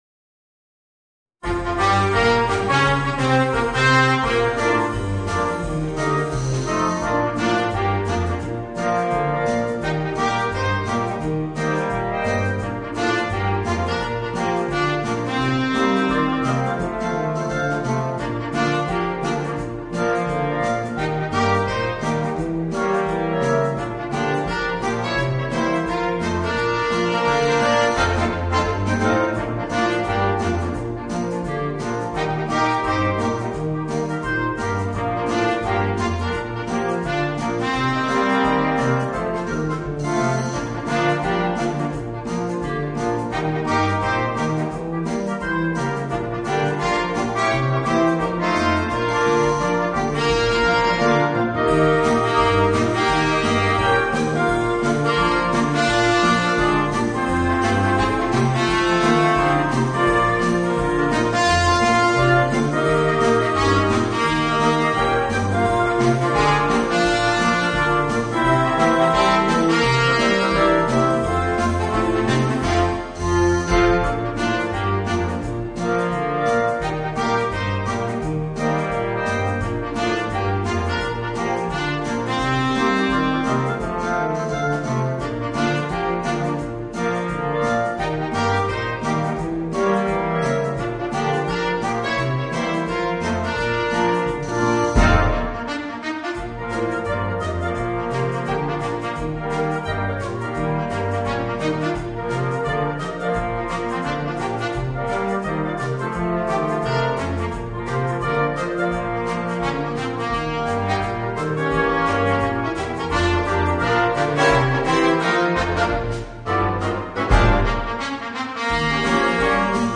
Voicing: 8 - Part Ensemble